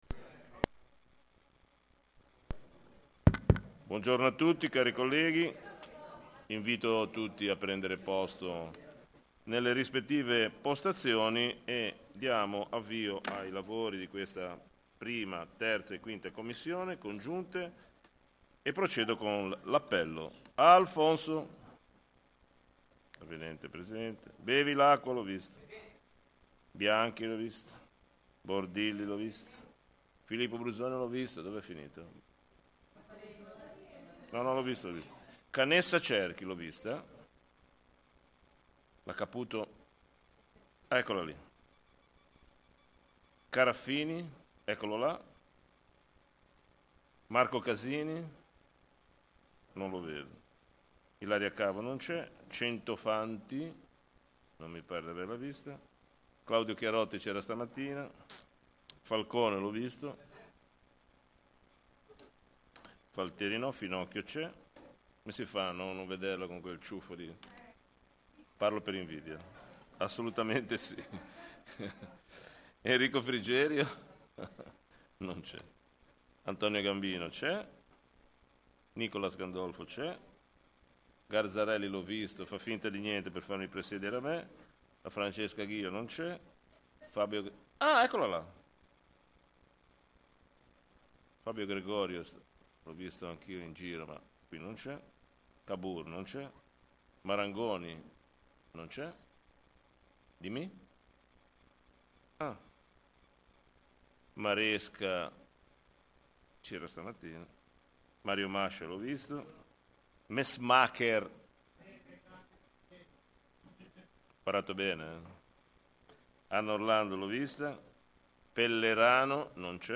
Commissione Consiliare 1-3-5 del 20.10.2025 ore 14.00 | Comune di Genova